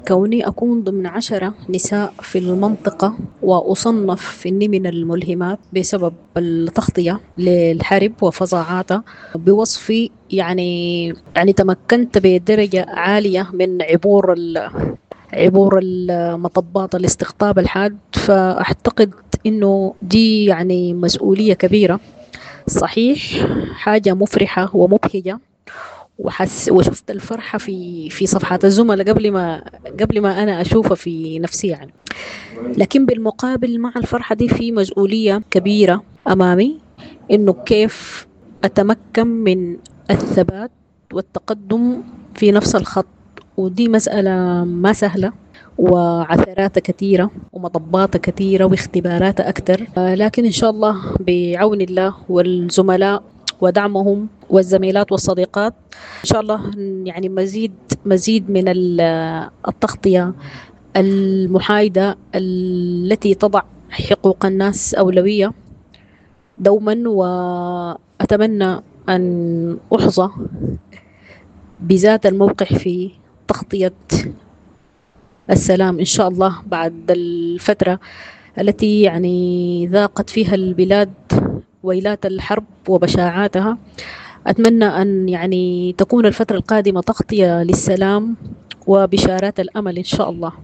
مقابلة خاصة